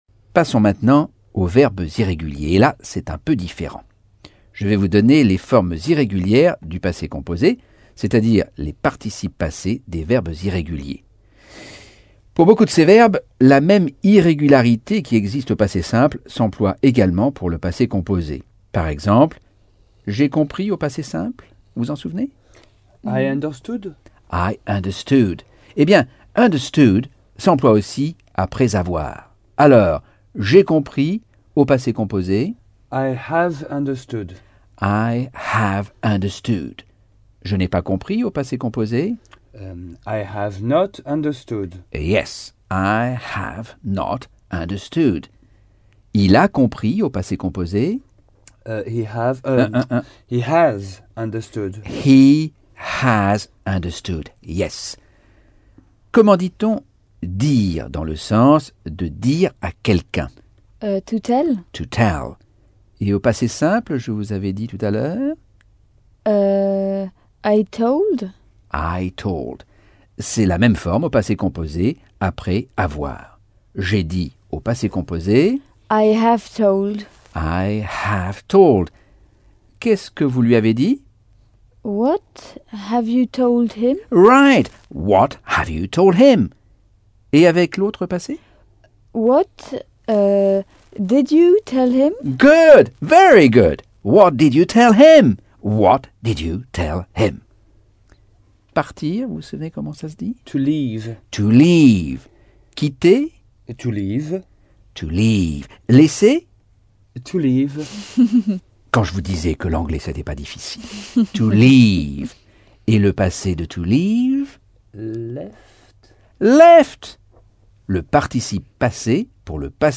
Leçon 7 - Cours audio Anglais par Michel Thomas - Chapitre 8